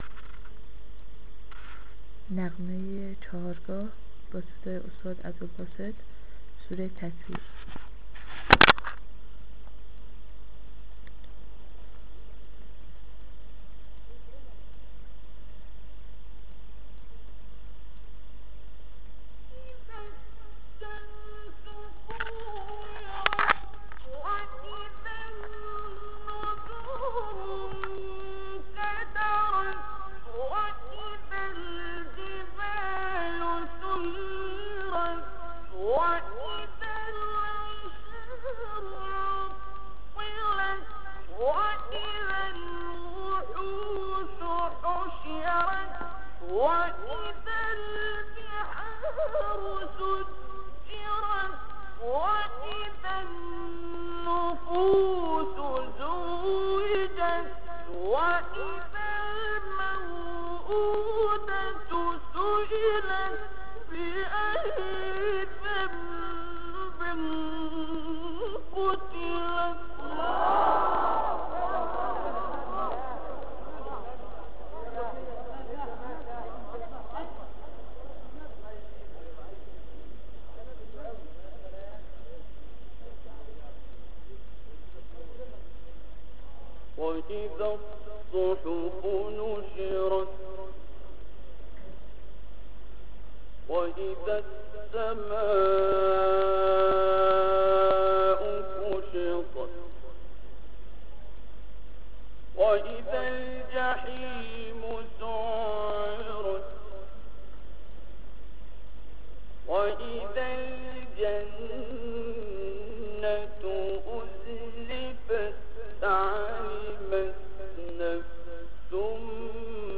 چهارگاه-عبدالباسط-سوره-تکویر.mp3